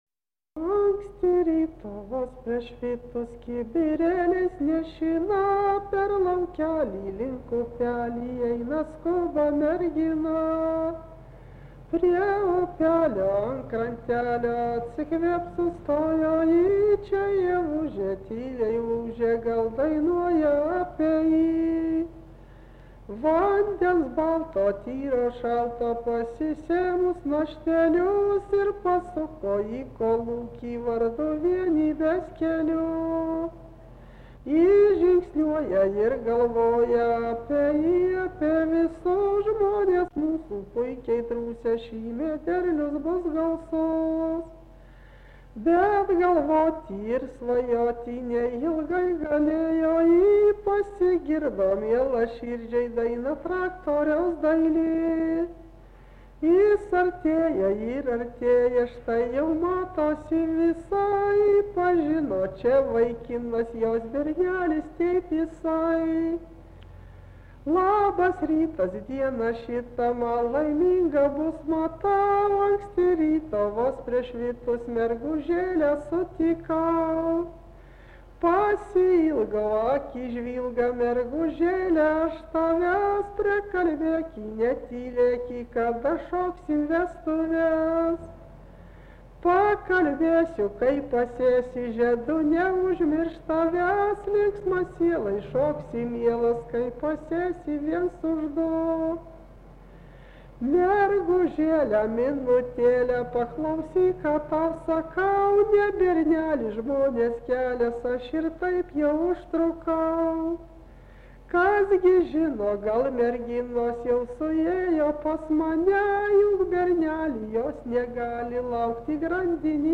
rauda, vestuvių
Atlikimo pubūdis vokalinis
Komentaras pradžioj